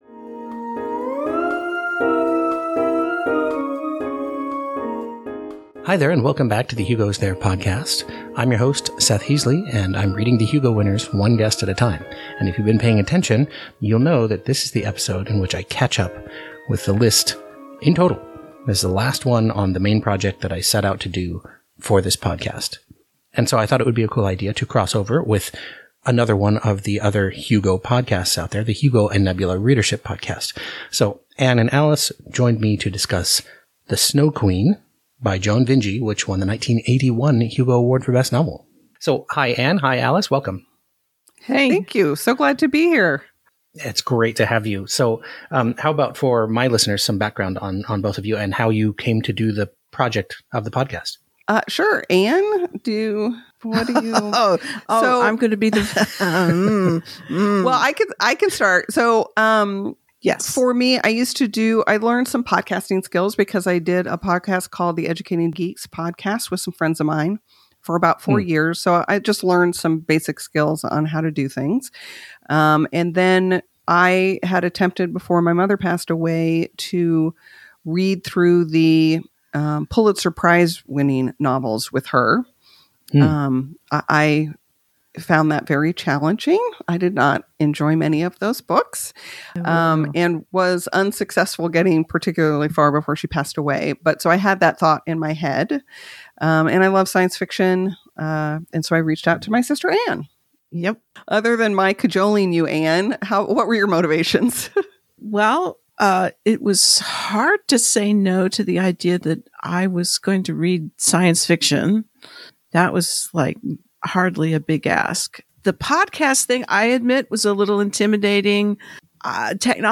discussion